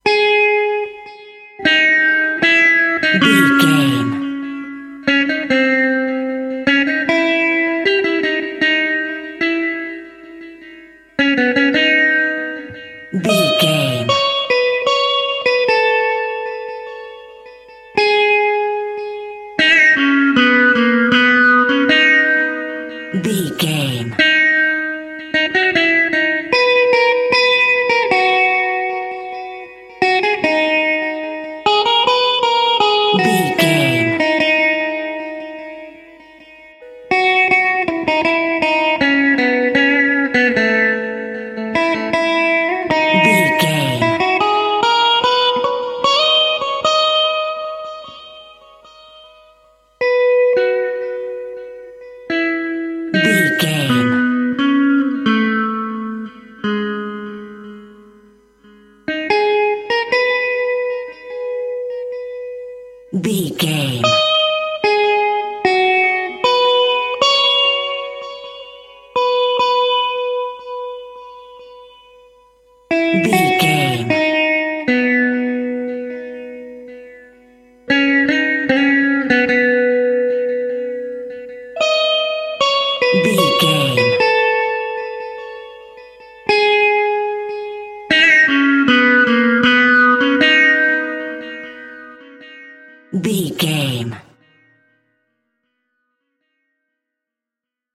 Aeolian/Minor
G#
World Music
percussion